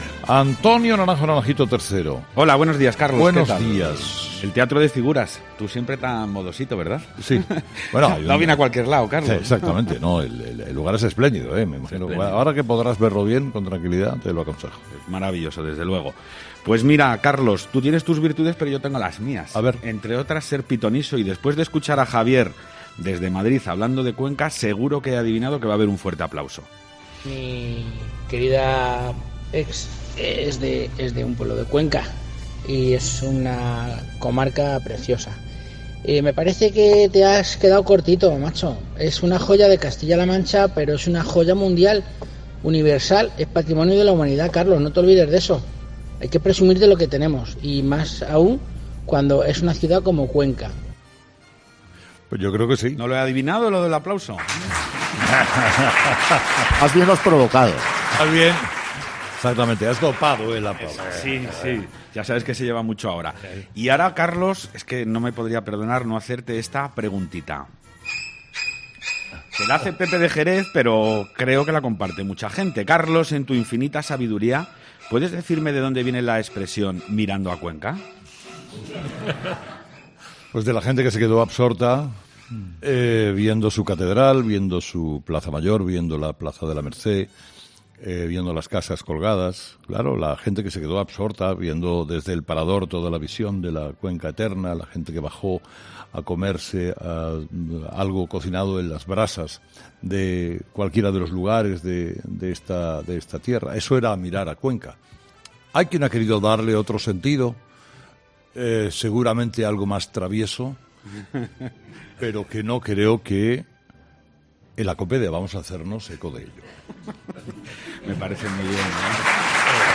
La tertulia de los oyentes es el espacio que Herrera ofrece a sus seguidores para que pongan sobre la mesa los temas de actualidad que más les preocupan.